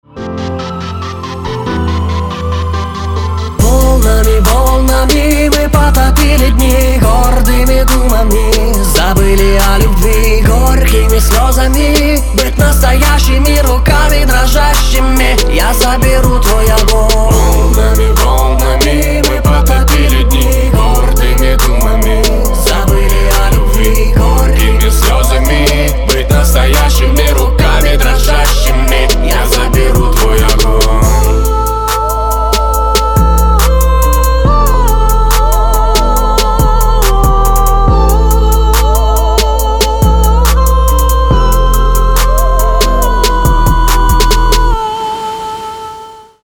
• Качество: 320, Stereo
Хип-хоп
грустные
русский рэп
мелодичные
красивая мелодия
лиричные